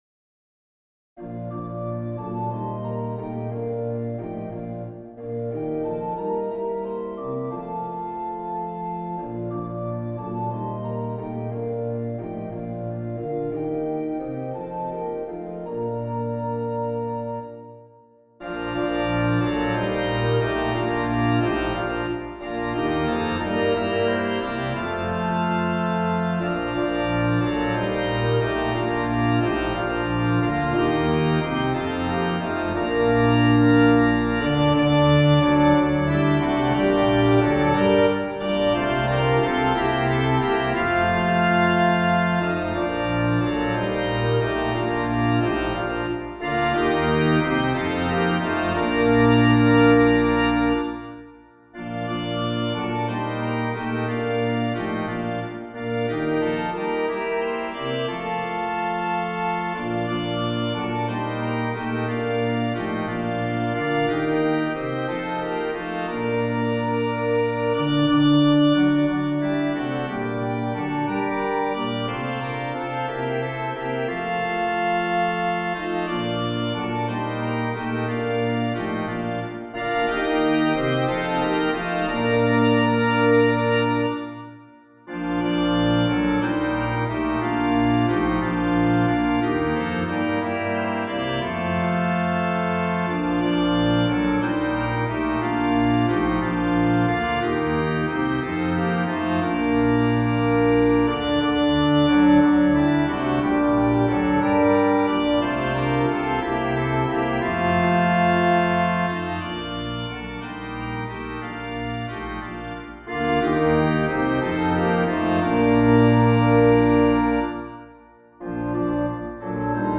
Carol, Organ, 5 Verses